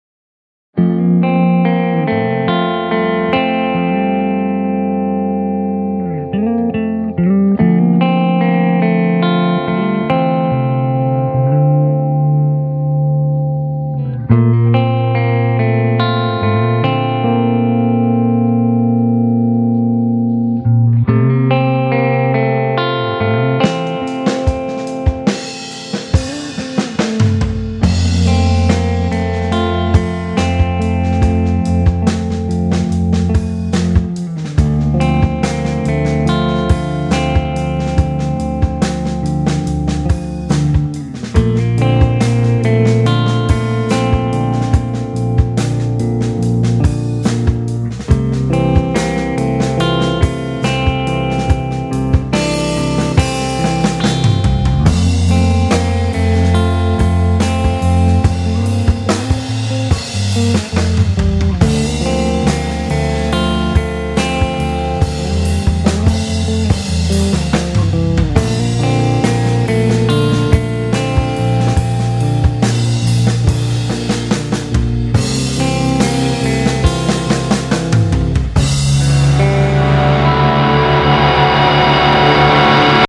本期音乐为日本后摇（Post-Rock）音乐专题。